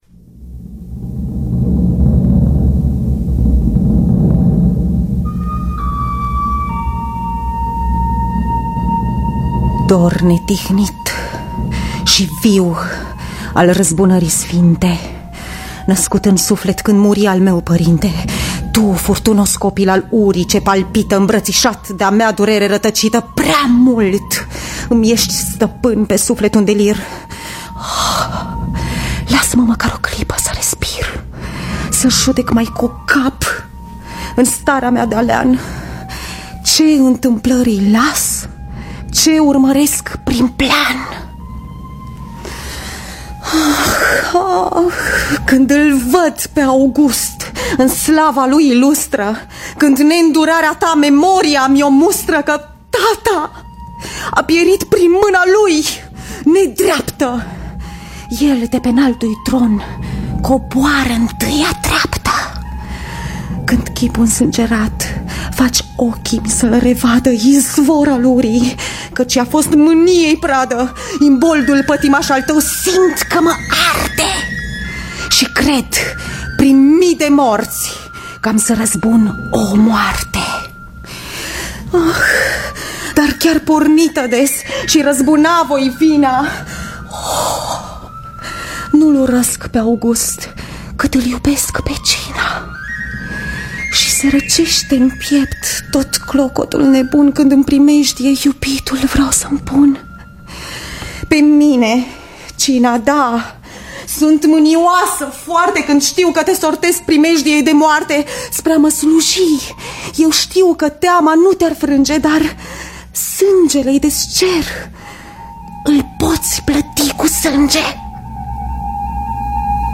Pierre Corneille – Cinna (Clementa Lui August) (2006) – Teatru Radiofonic Online